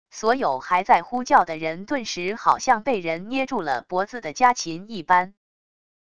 所有还在呼叫的人顿时好像被人捏住了脖子的家禽一般wav音频生成系统WAV Audio Player